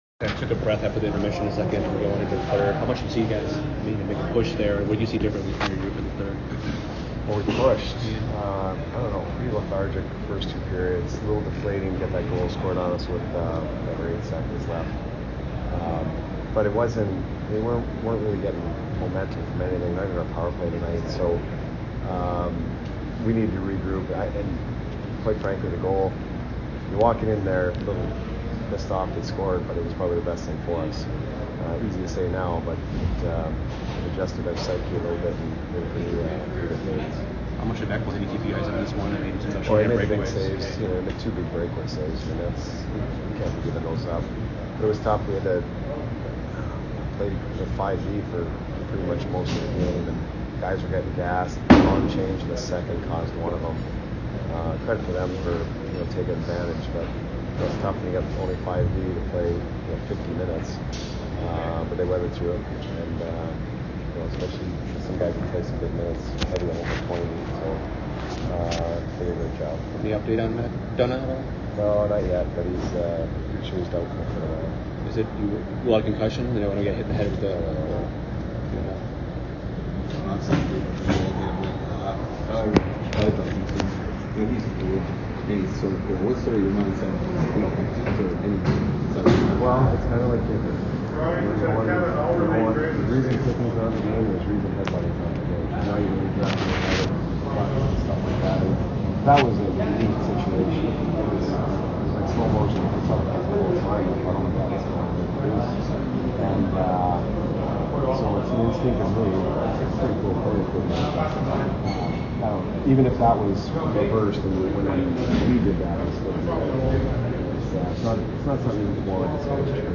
Jon Cooper Post - Game At Ottawa Jan. 4, 2020